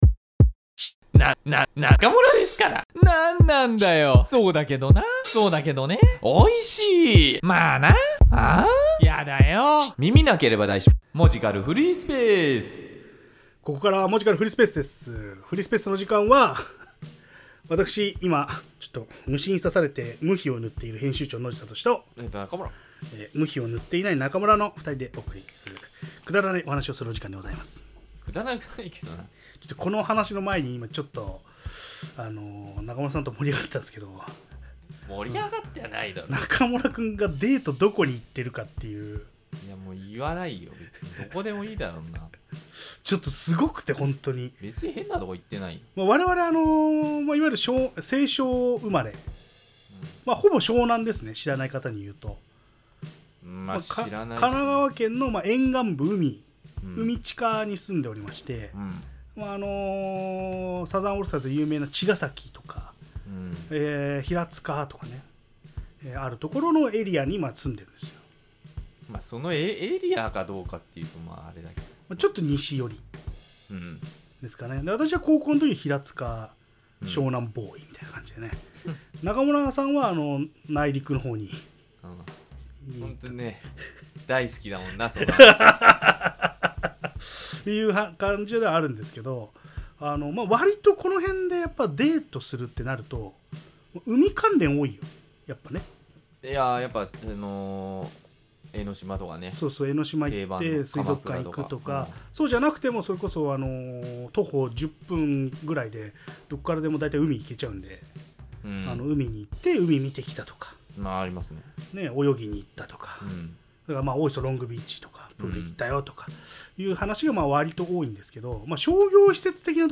ここ最近の出来事てんこもりの雑談ラジオ。